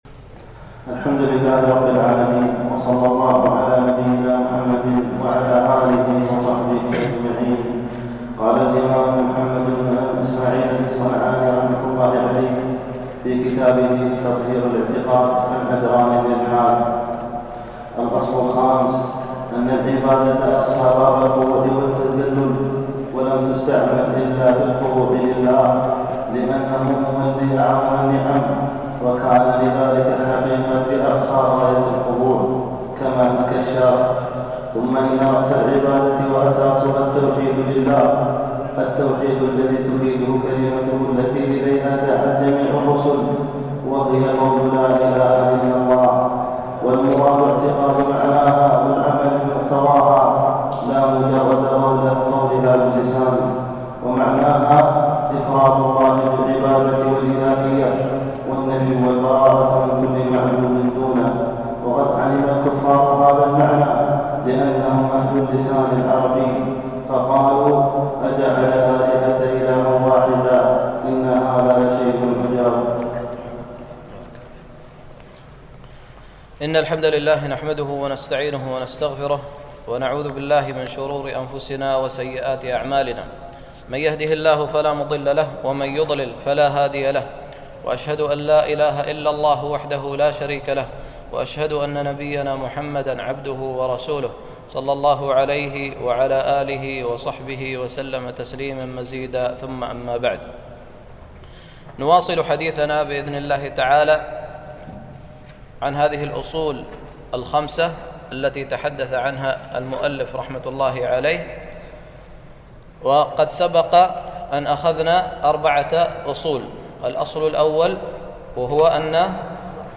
شرح الأصول الخمسة - الدرس الثالث